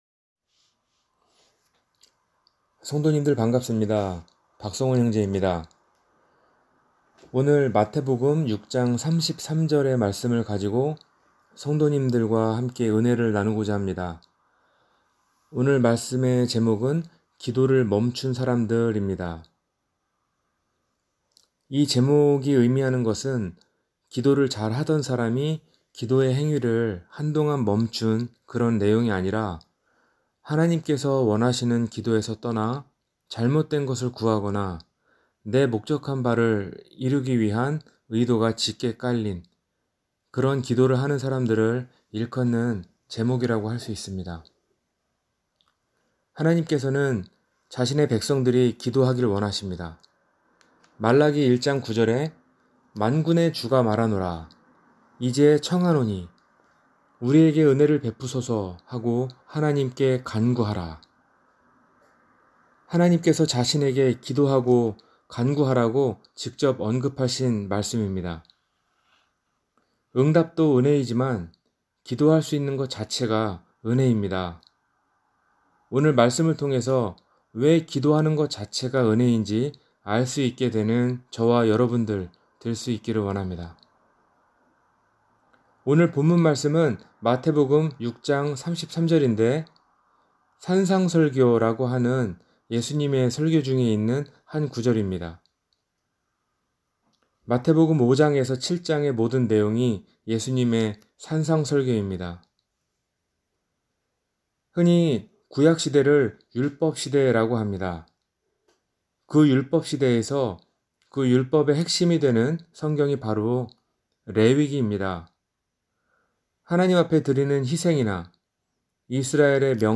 기도를 멈춘 사람들 – 주일설교